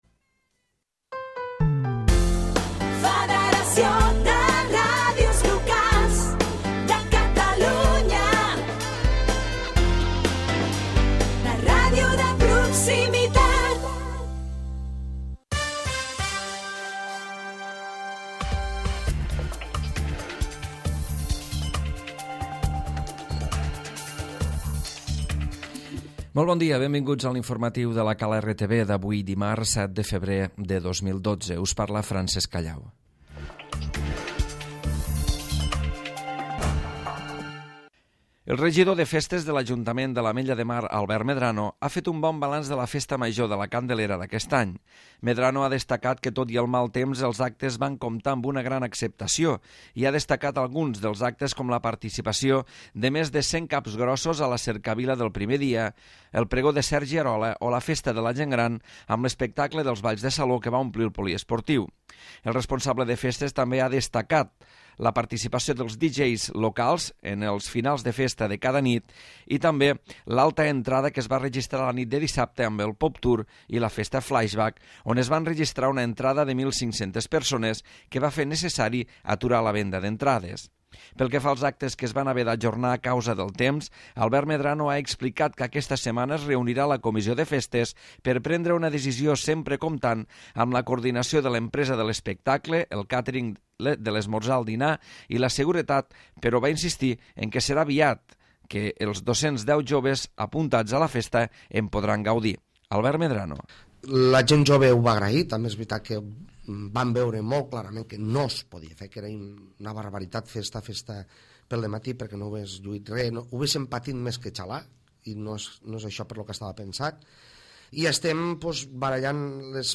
Butlletí Informatiu